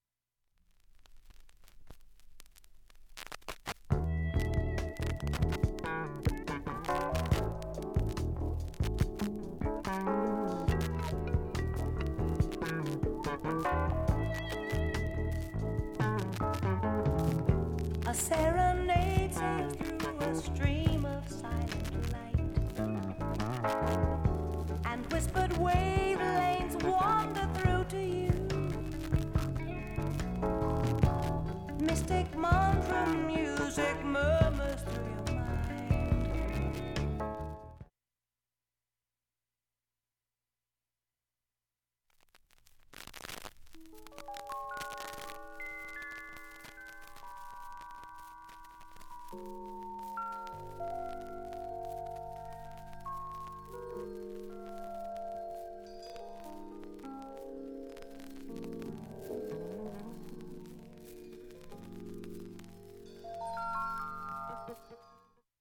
明記はしないかすかなレベルです。
周回サーフェスが出ていますが